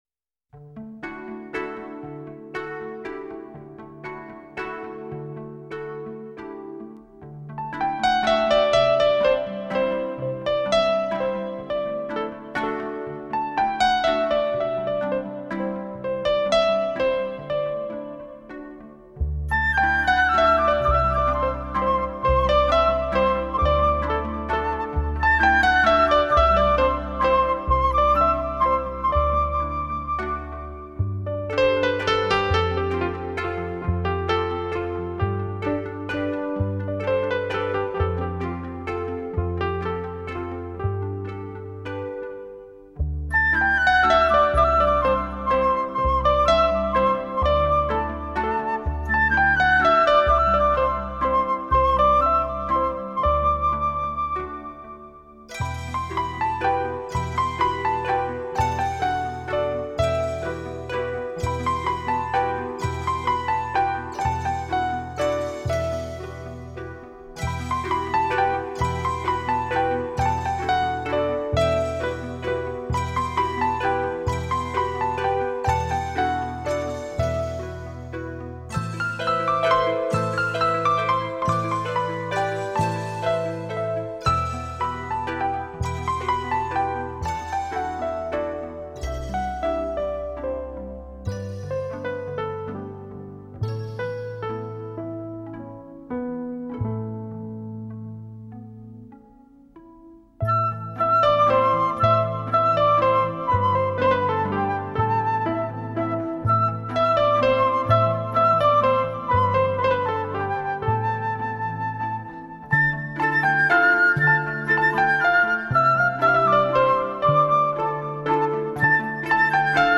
温柔地象微风细语般轻轻流过你的身体，洁净烦嚣。